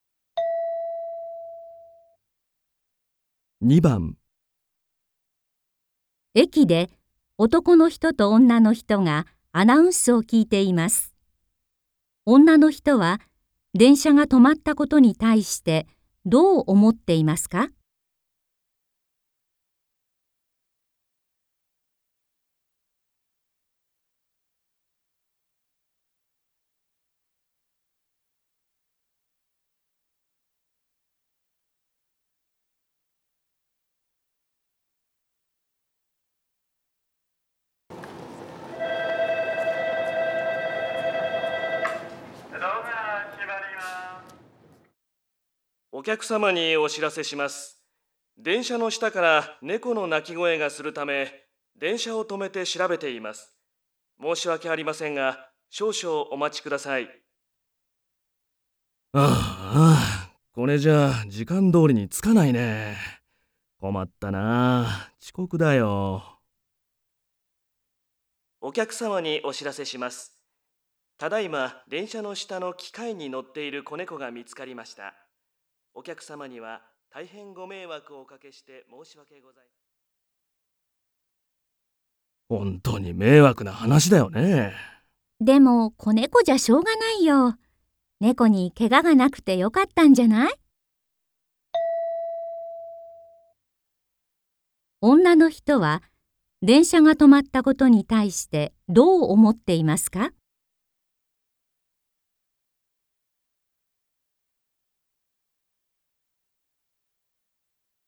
問題 4 ［聴解］